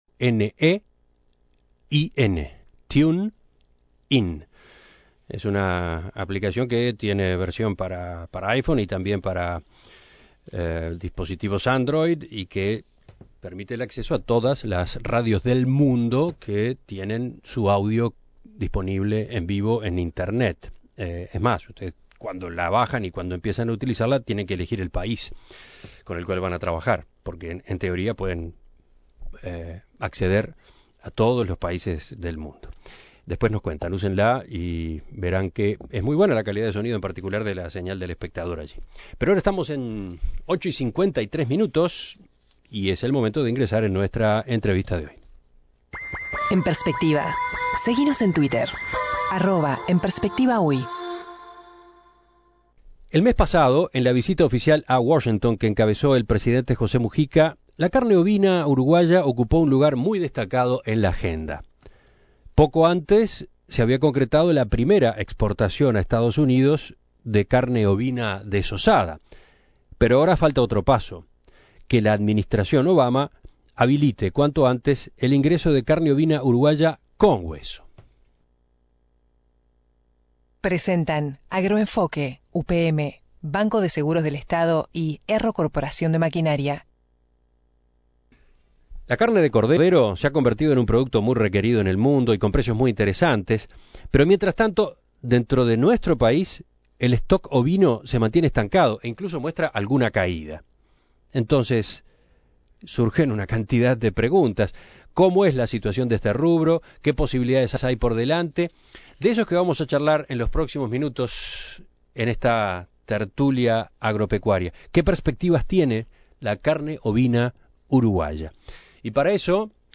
En esta oportunidad la entrevista